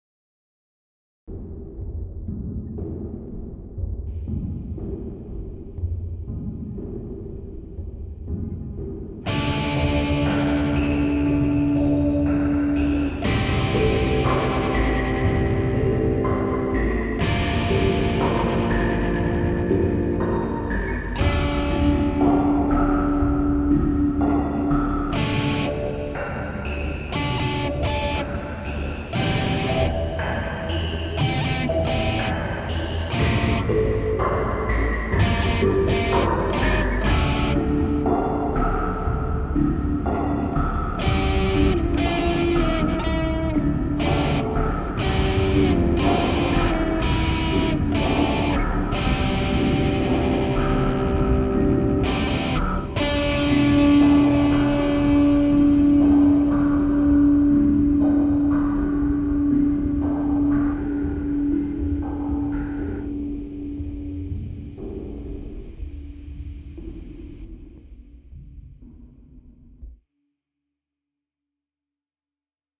Bei beiden Libraries handelt es sich um Wave Table Synths, welche unter Kontakt, oder dem kostenlosen Kontakt Player 5.5.2, oder höher laufen.